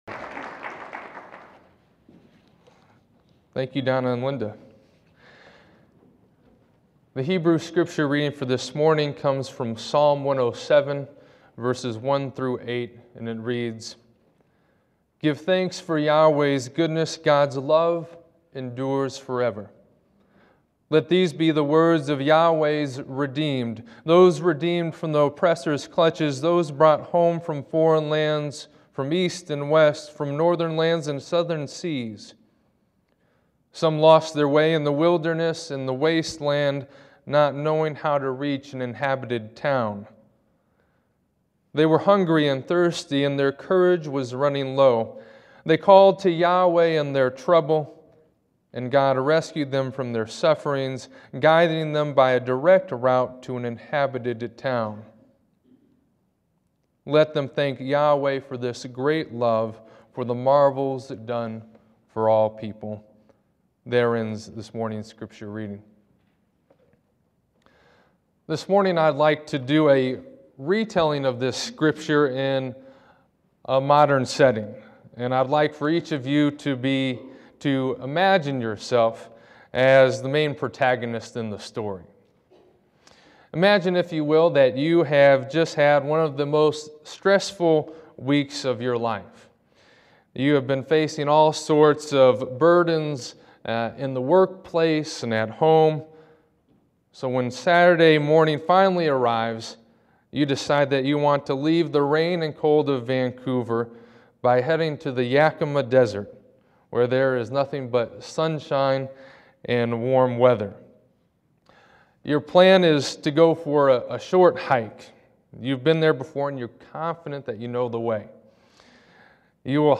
To listen to this sermon which considers research indicating religious people are more likely to be good neighbors and citizens, click here.